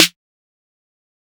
Snare Groovin 6.wav